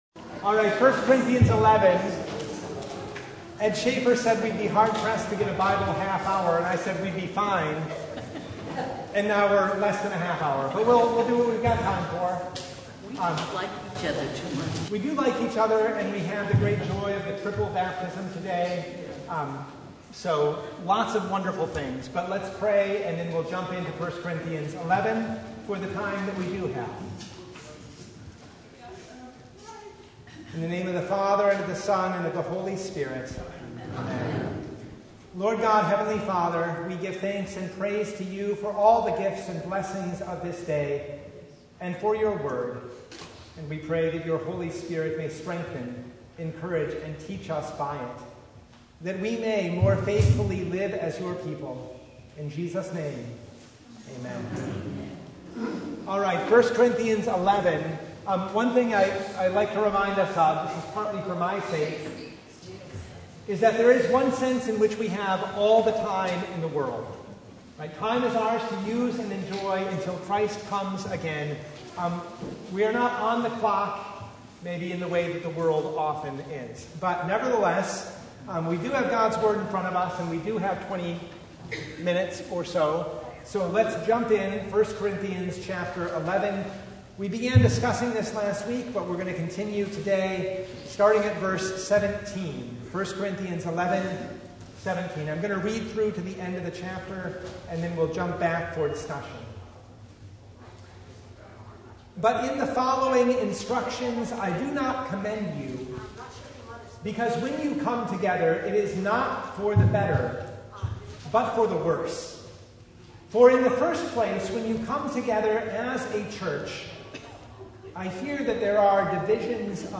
1 Corinthians 11:17-34 Service Type: Bible Hour Topics: Bible Study « The Fourth Sunday in Angels’ Tide